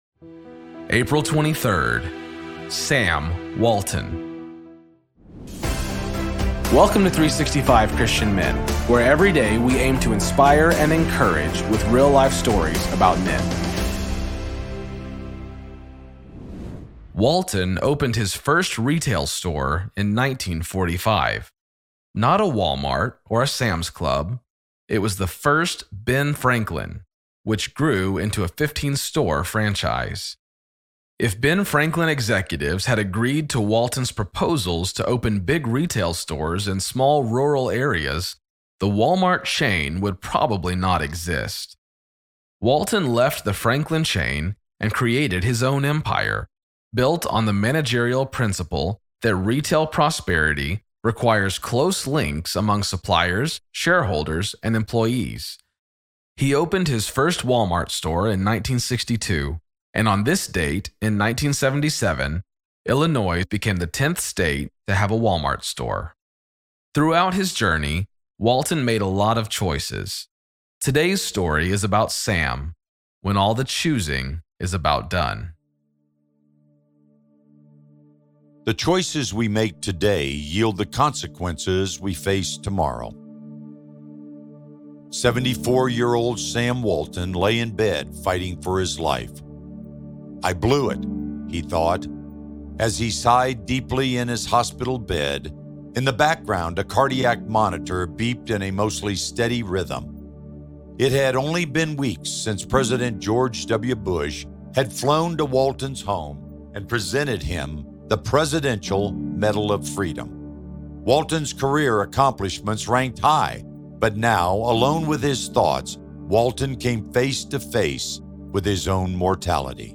Story read